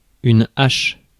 Ääntäminen
Ääntäminen France: IPA: /aʃ/ Haettu sana löytyi näillä lähdekielillä: ranska Käännös Substantiivit 1. секира 2. брадва 3. топор 4. балтия Muut/tuntemattomat 5. бра́два {f} 6. секи́ра 7. топо́р Suku: f .